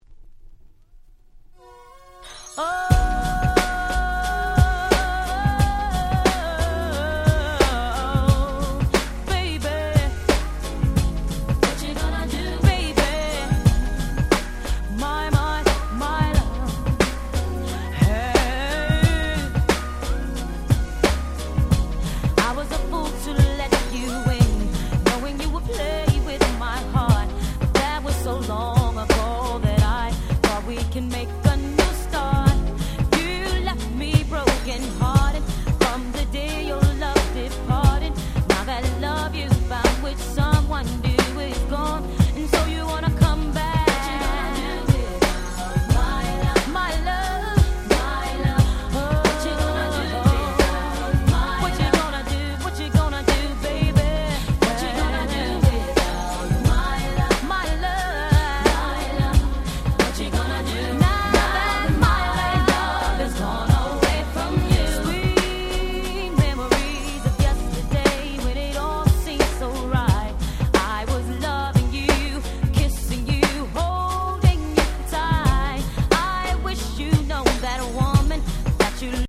93' Smash Hit R&B !!
US Promo Only Remix !!